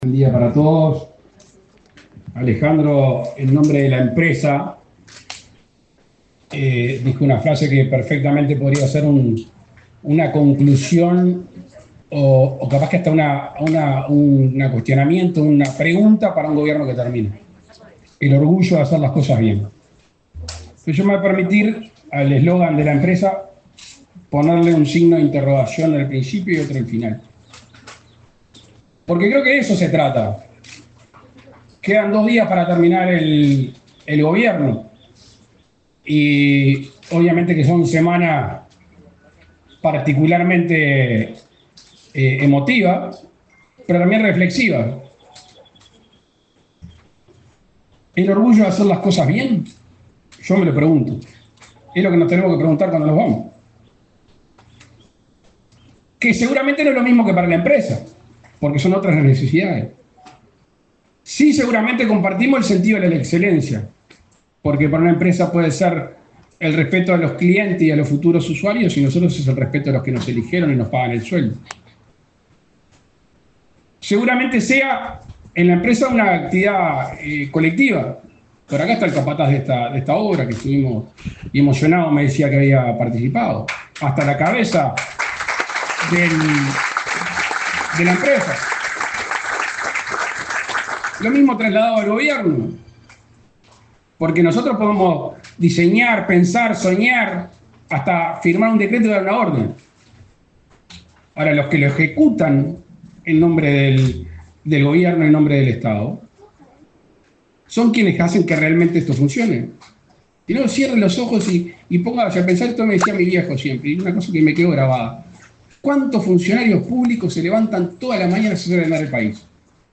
Palabras del presidente Luis Lacalle Pou
Palabras del presidente Luis Lacalle Pou 27/02/2025 Compartir Facebook X Copiar enlace WhatsApp LinkedIn El presidente de la República, Luis Lacalle Pou, encabezó, este jueves 27, la inauguración de obras de reforma de ruta n.° 8 en el departamento de Treinta y Tres.